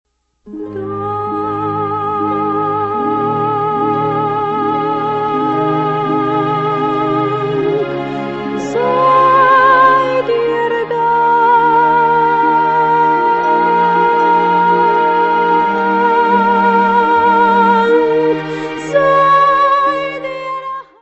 piano
: stereo; 12 cm + folheto
Área:  Música Clássica